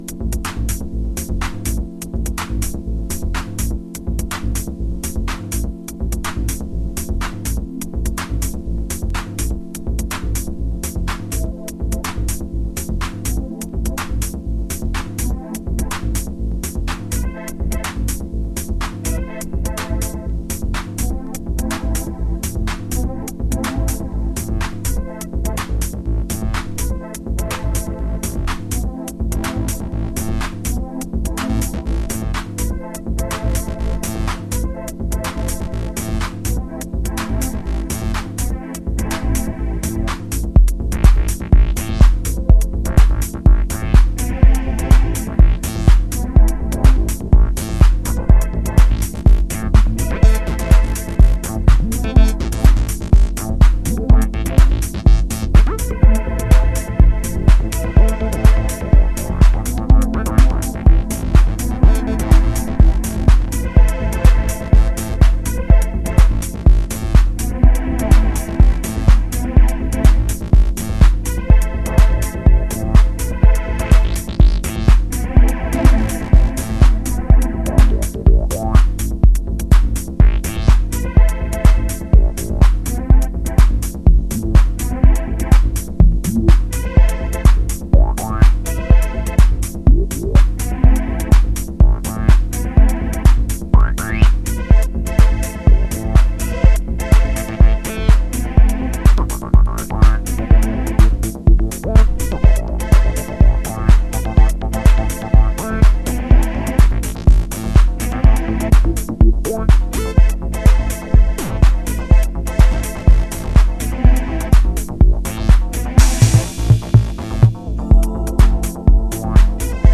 低いところをフローティングするのに持って来いの2トラック。